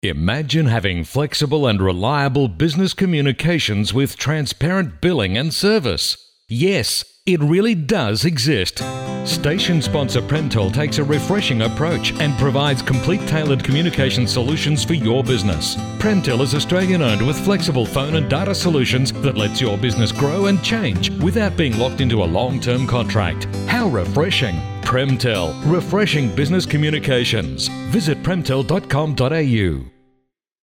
As heard on Radio Hope FM103.2